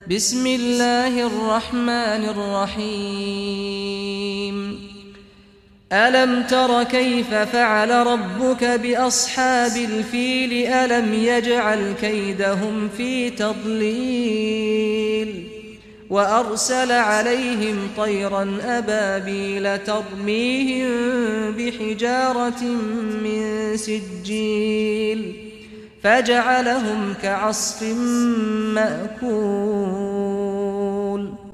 Surah Al-Fil Recitation by Sheikh Saad al Ghamdi
Surah Al-Fil, listen or play online mp3 tilawat / recitation in Arabic in the beautiful voice of Imam Sheikh Saad al Ghamdi.